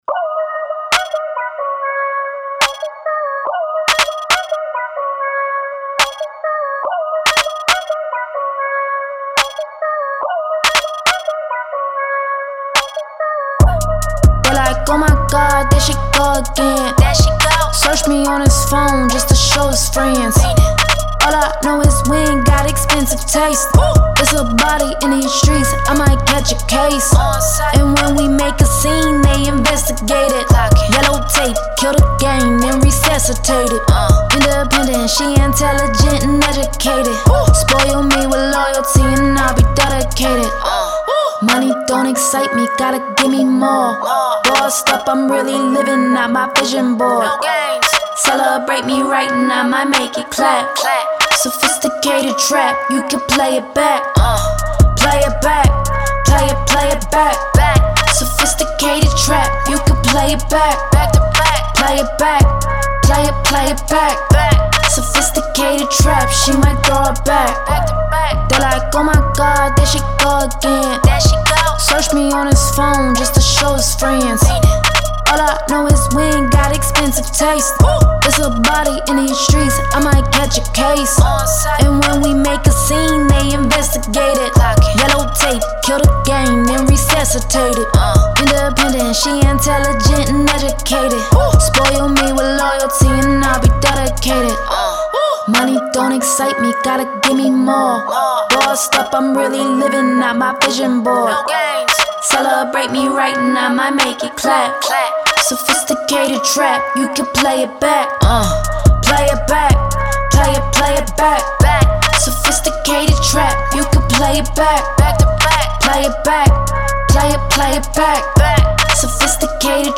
Hip Hop
Ab Minor